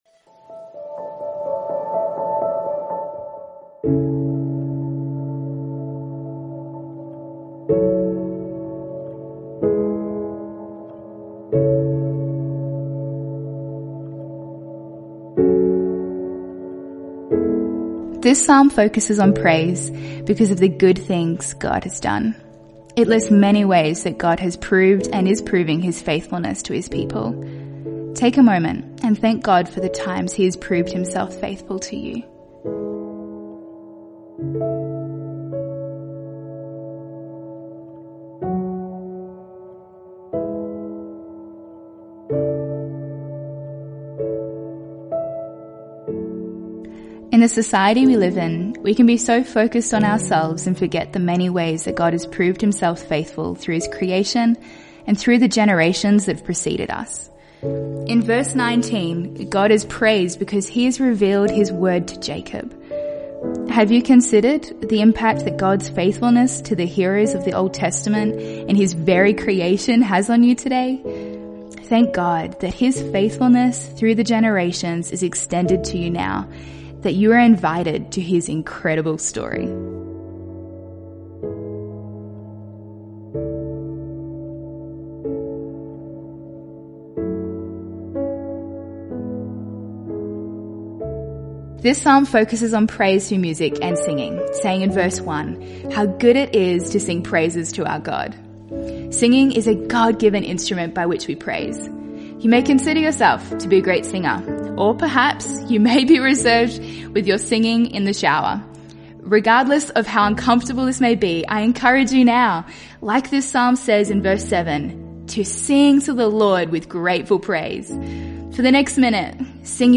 After you have completed today’s reading from the Bible, we encourage you to set aside a moment to listen along to the audio guide provided as we pray and allow God to speak to us through His word.